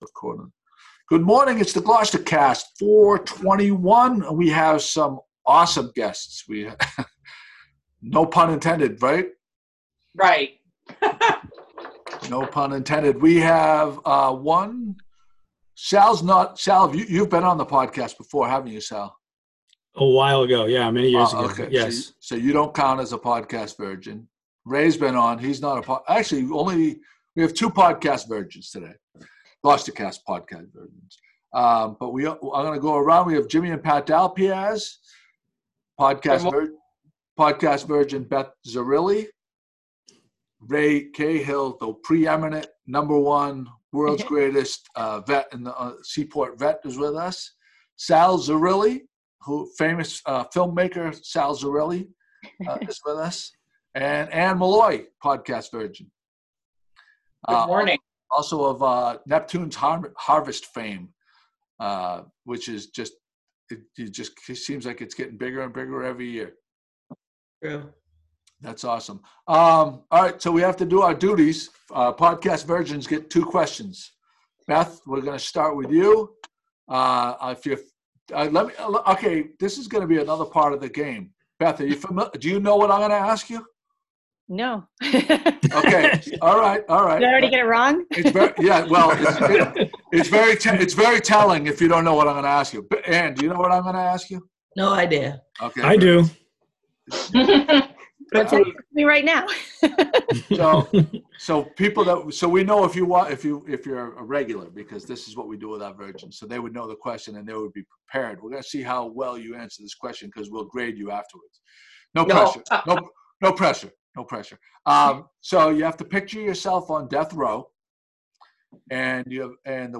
GloucesterCast 421 Livestream